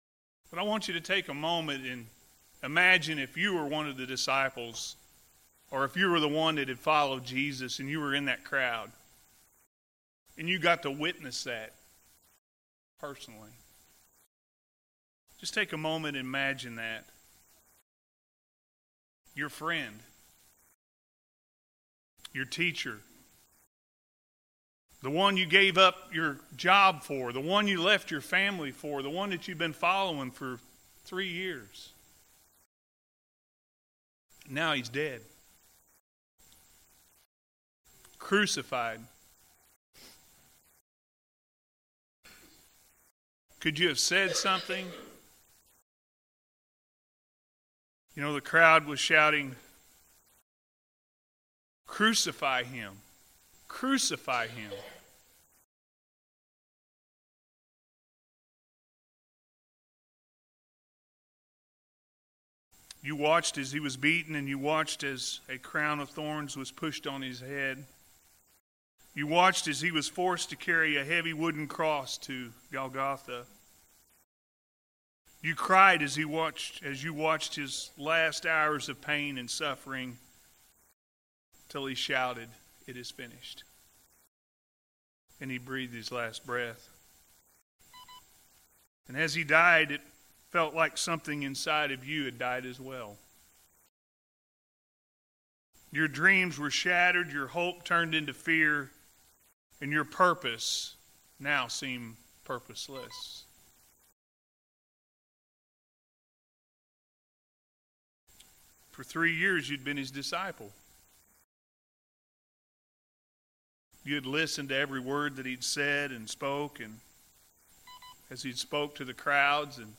He Is Risen-A.M. Service – Anna First Church of the Nazarene